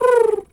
pgs/Assets/Audio/Animal_Impersonations/pigeon_2_call_06.wav at master
pigeon_2_call_06.wav